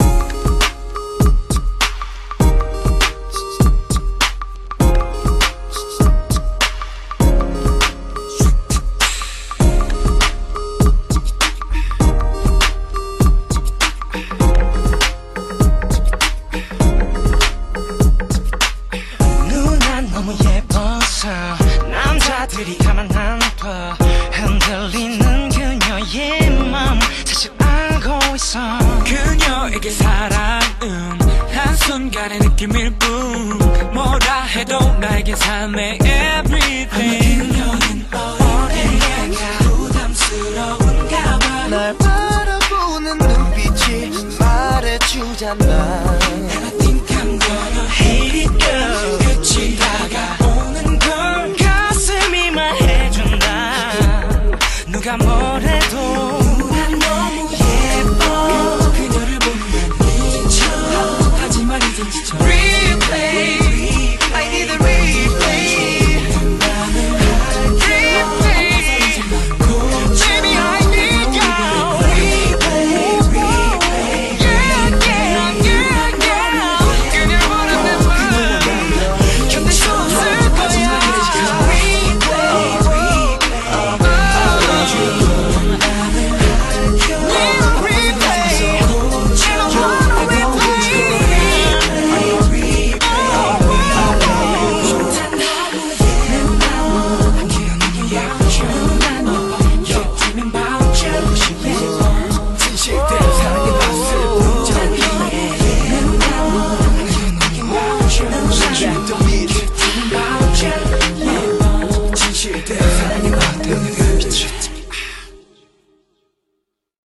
BPM100
Audio QualityMusic Cut
CommentsOne of the first tracks from the K-Pop group.